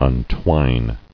[un·twine]